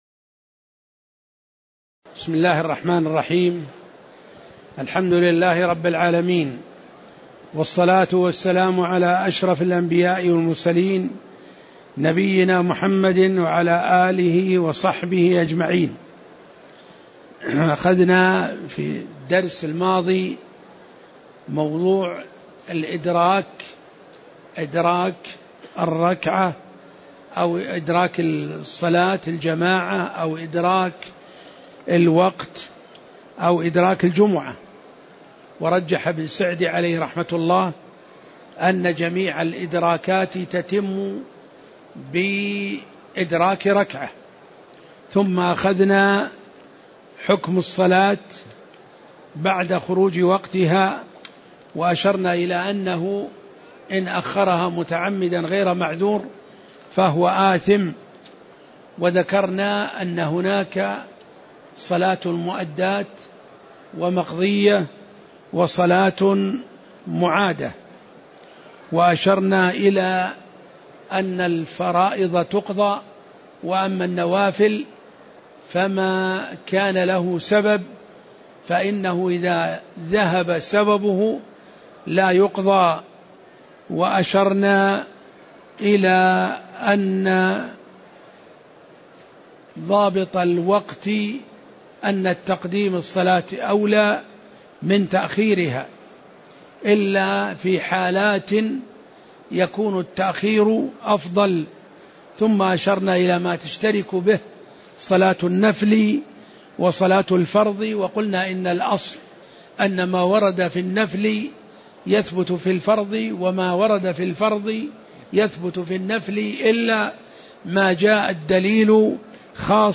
تاريخ النشر ١٦ شوال ١٤٣٨ هـ المكان: المسجد النبوي الشيخ